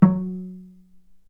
vc_pz-F#3-ff.AIF